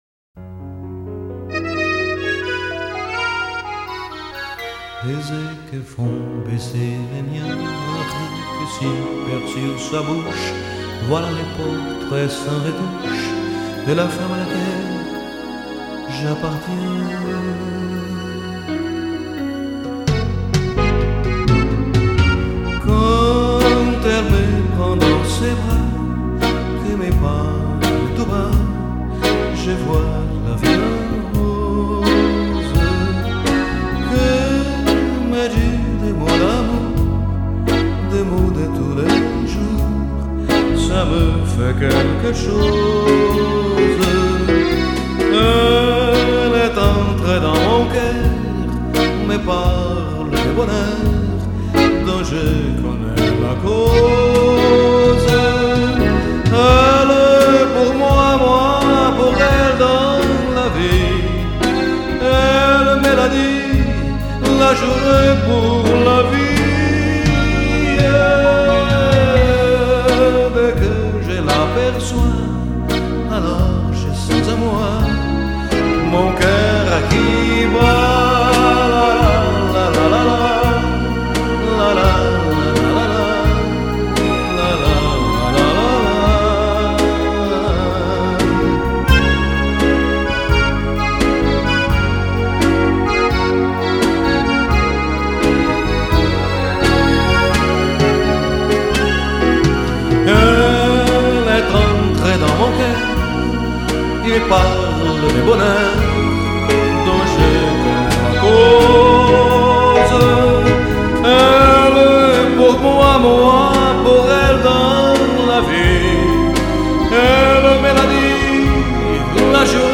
este era o título do seu CD com músicas românticas